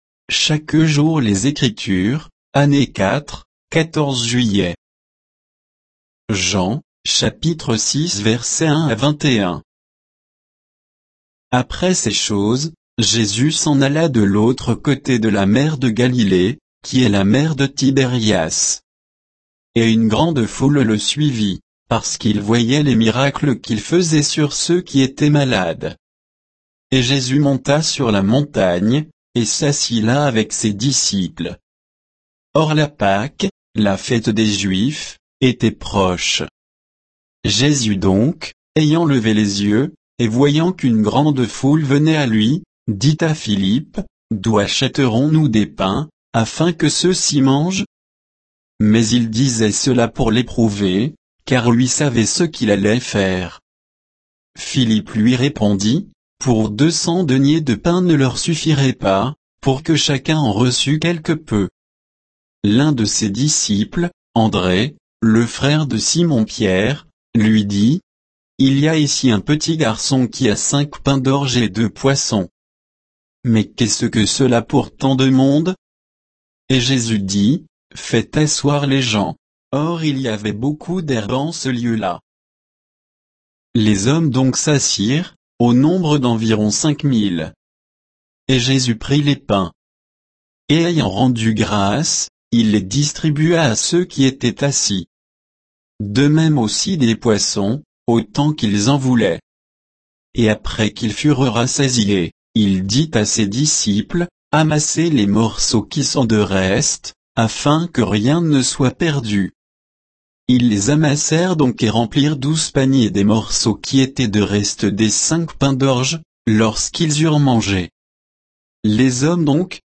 Méditation quoditienne de Chaque jour les Écritures sur Jean 6